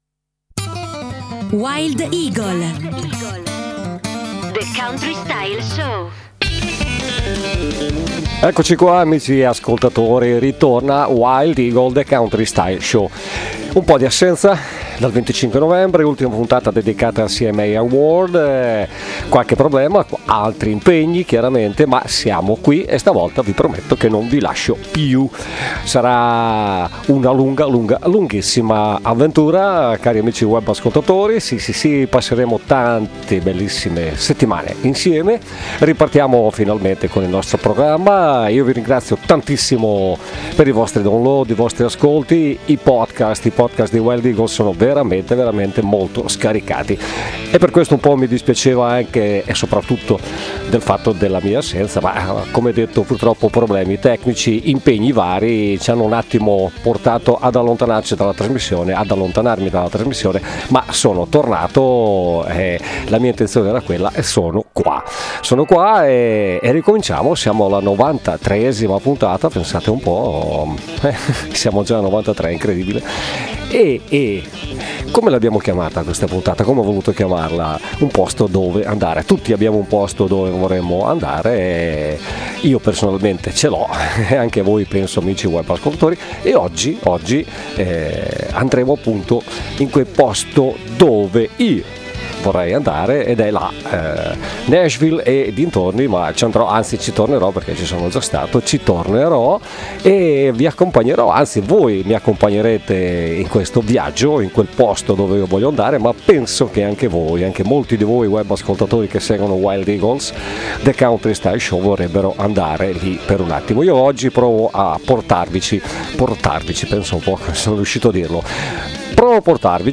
le terre della Country Music.